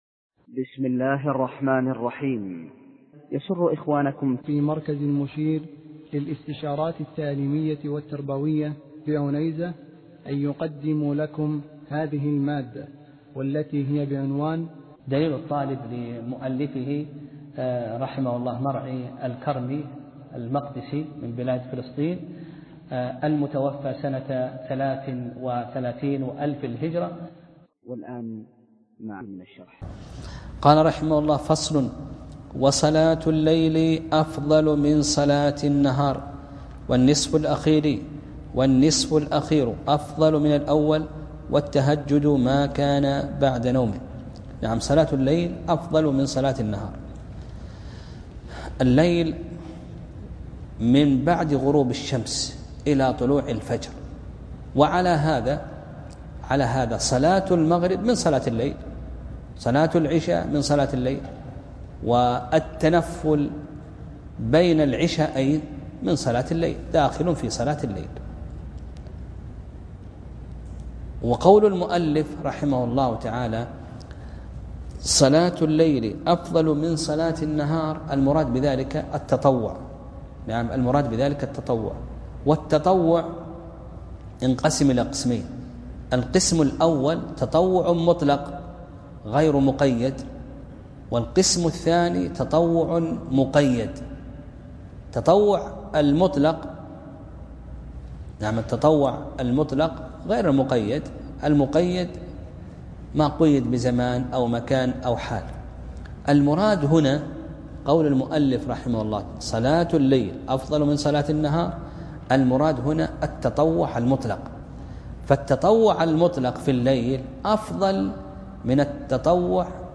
درس (5) : باب صلاة التطوع